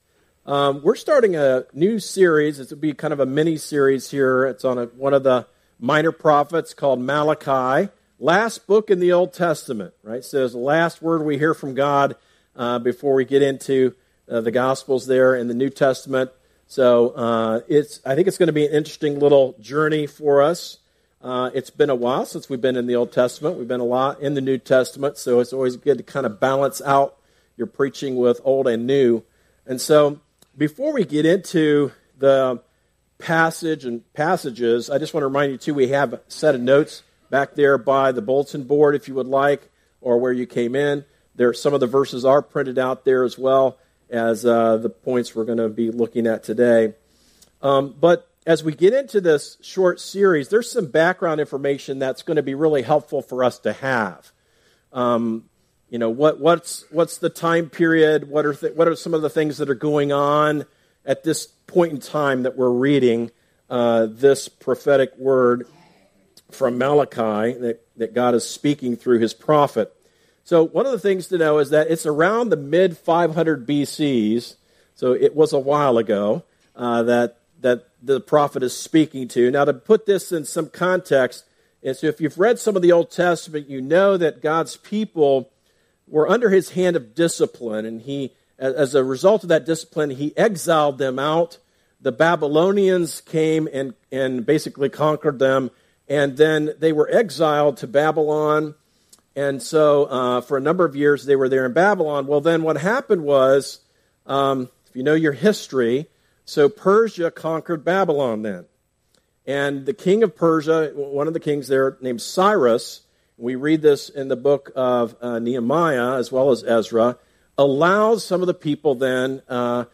A message from the series "Return To Me."